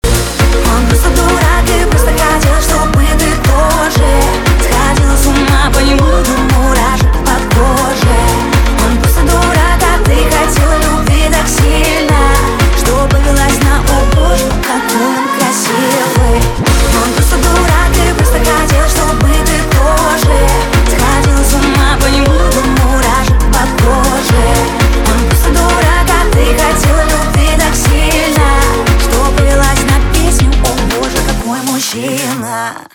поп
битовые , басы , качающие , грустные